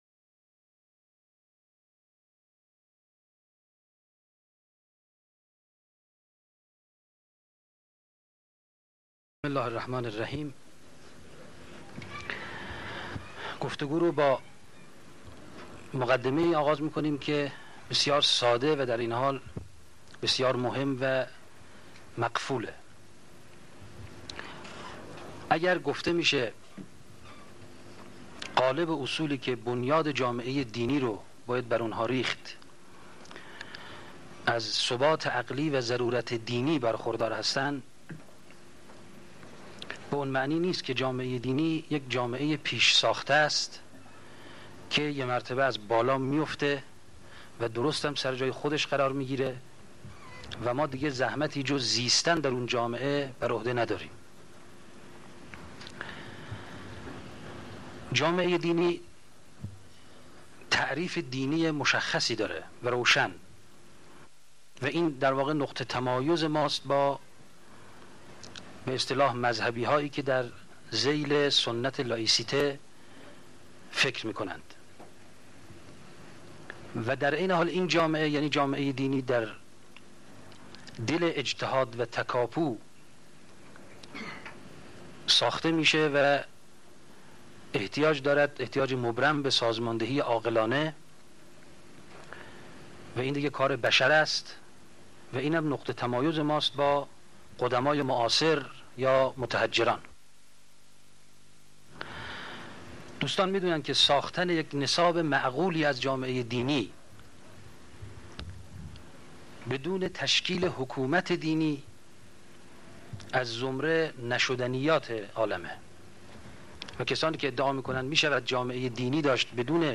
دانشگاه علوم قضایی تهران - نشست دانشجویی